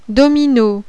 Objet evenement jeu PIOCHE (JEU)
DOMINO.wav